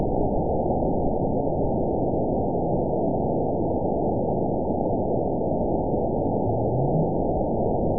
event 920449 date 03/26/24 time 01:30:52 GMT (1 year, 1 month ago) score 9.56 location TSS-AB02 detected by nrw target species NRW annotations +NRW Spectrogram: Frequency (kHz) vs. Time (s) audio not available .wav